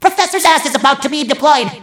mvm_bomb_alerts10.mp3